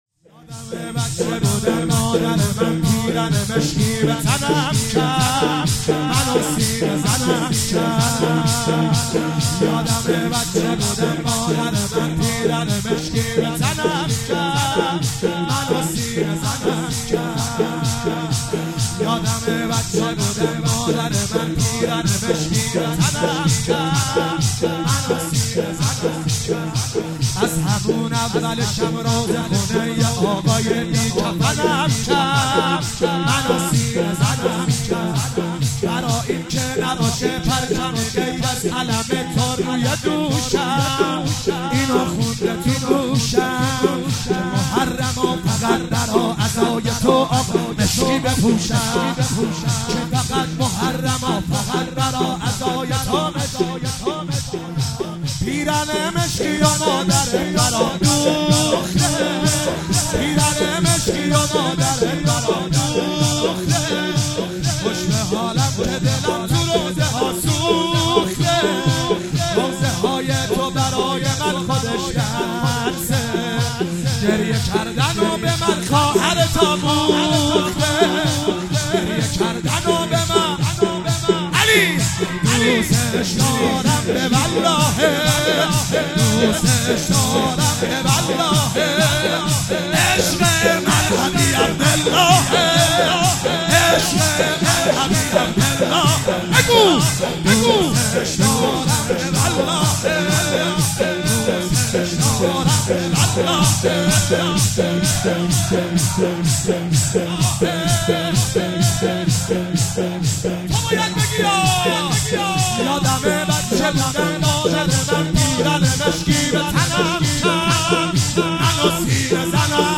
شب اول محرم 96 - شور - یادمه بچه بودم مادر من پیرهن